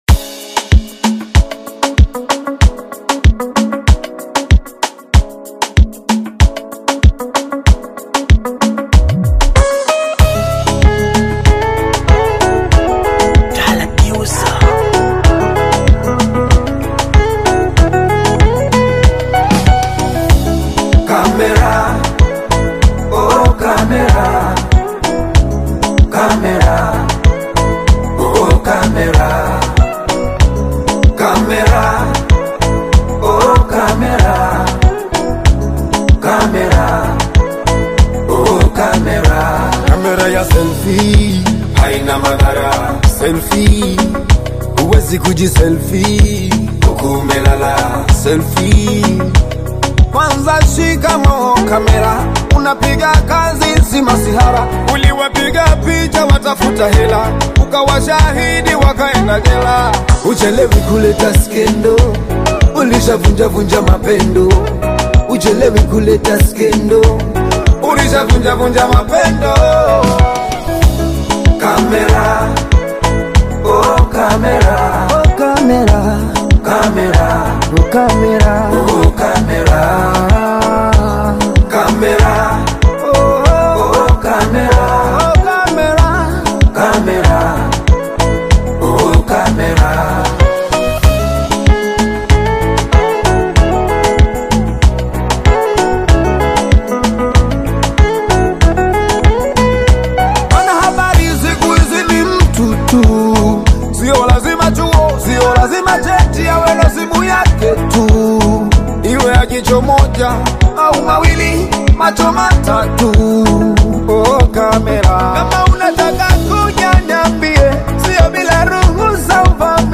reflective Bongo Flava single
smooth vocal delivery
Latest Bongo Flava, Afrobeat and more updates 🔥